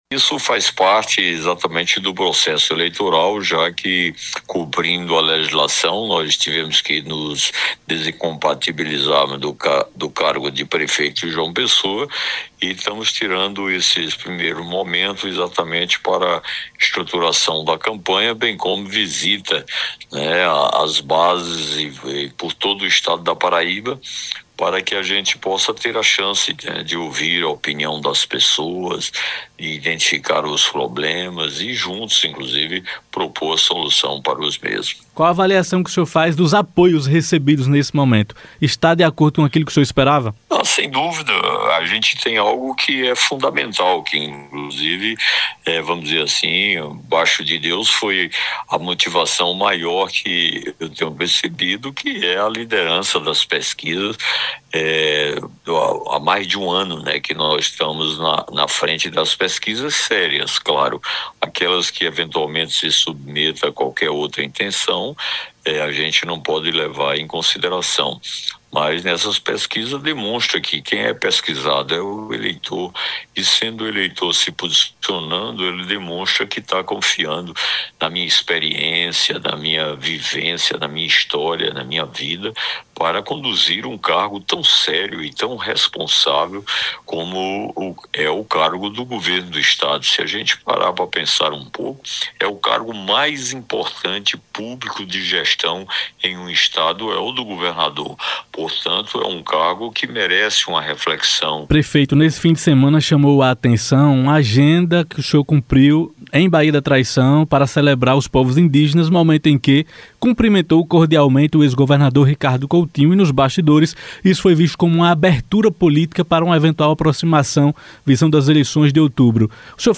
Cícero Lucena é entrevistado na Sabatina Jornal da Paraíba CBN. Reprodução da CBN